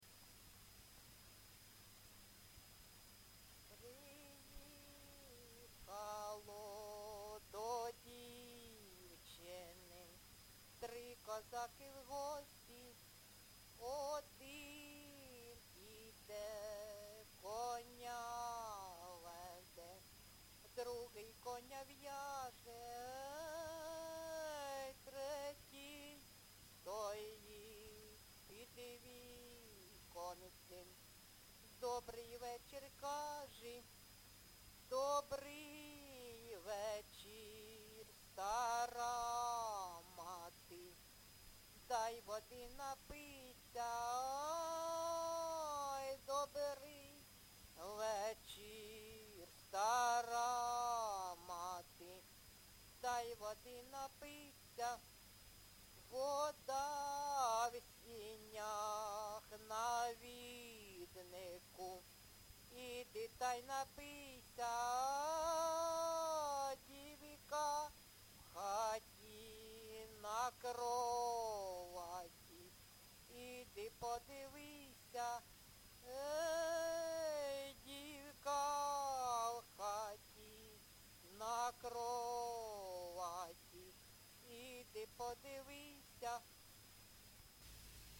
ЖанрПісні з особистого та родинного життя, Козацькі
Місце записум. Ровеньки, Ровеньківський район, Луганська обл., Україна, Слобожанщина